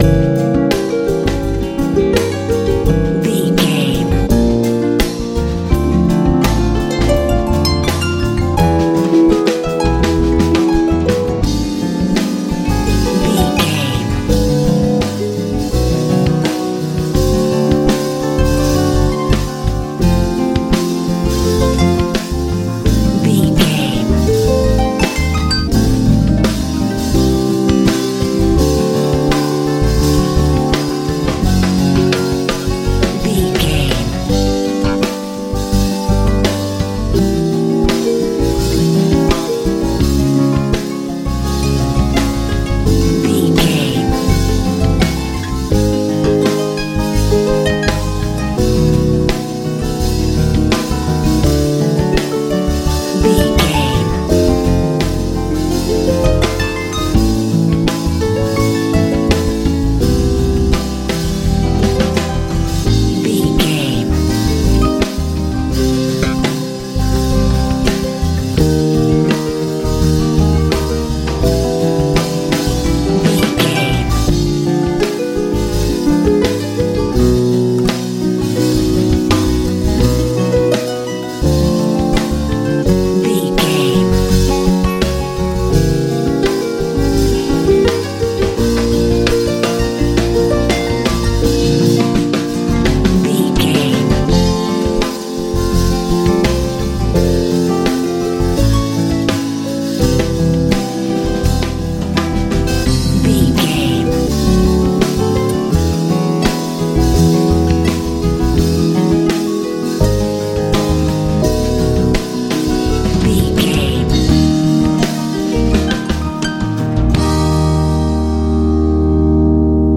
soul rnb feel...also harp in song
Ionian/Major
bright
bass guitar
drums
acoustic guitar
calm
cheerful/happy